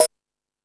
Hip House(38).wav